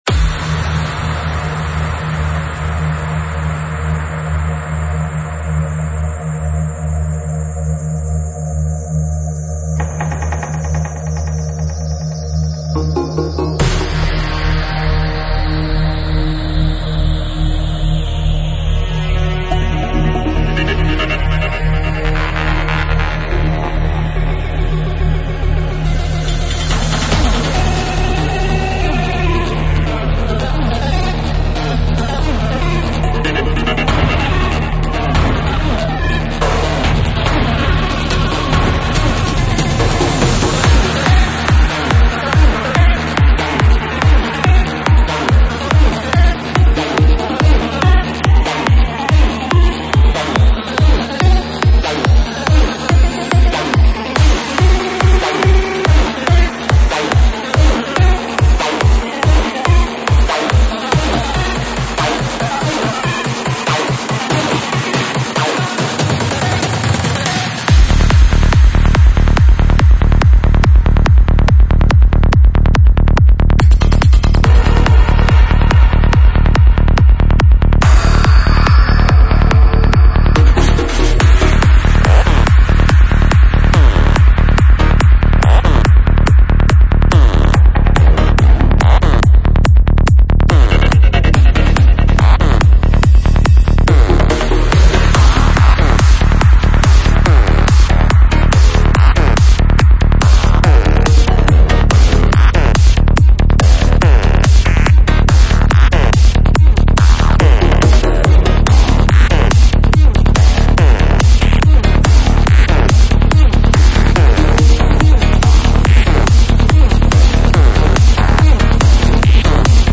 Файл в обменнике2 Myзыкa->Psy-trance, Full-on
Стиль: Trance / Psy Trance